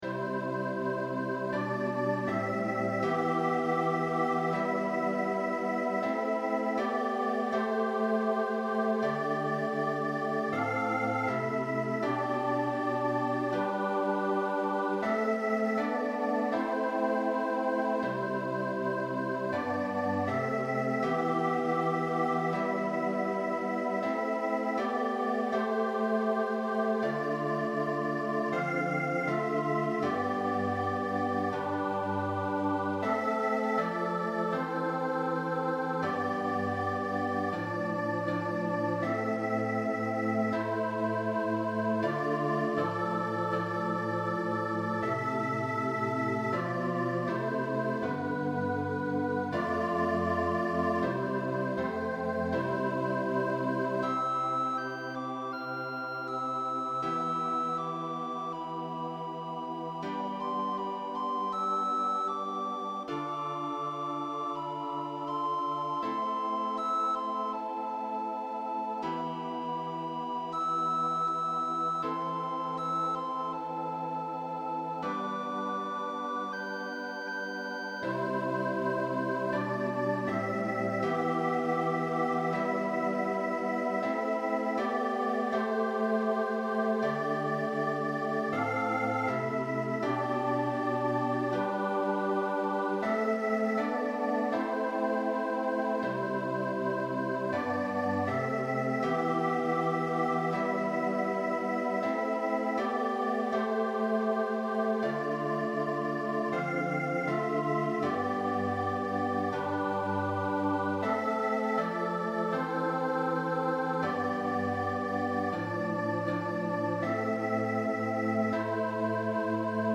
A Christmas hymn for chorus with Soprano Recorder, with text by Anne Brontë.
SATB Chorus with Soprano Recorder
MP3 rendering